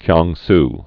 (kyängs)